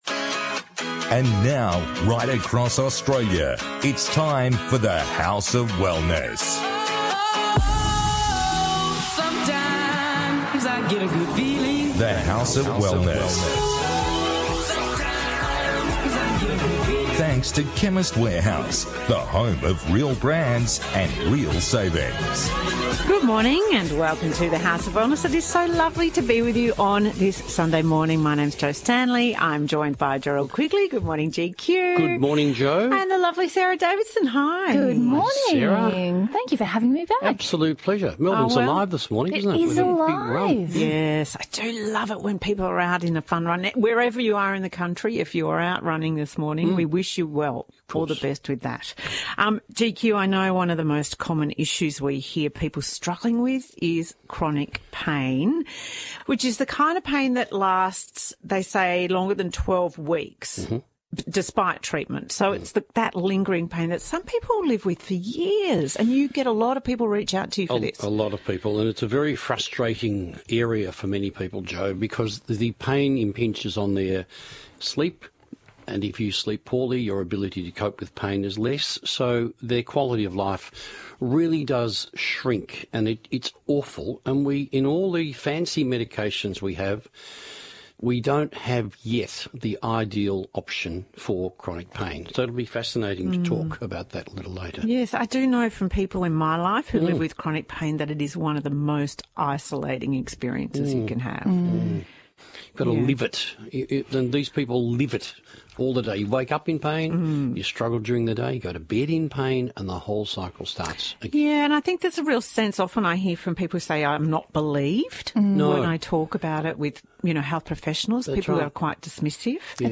On this week’s The House of Wellness radio show: